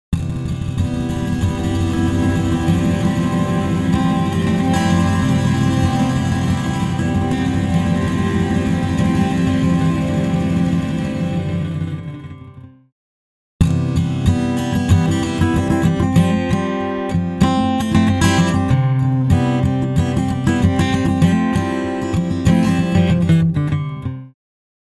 Unique Multi-Tap Delay
UltraTap | Electric Guitar | Preset: Verb in Space
Guitar-Verb-in-Space.mp3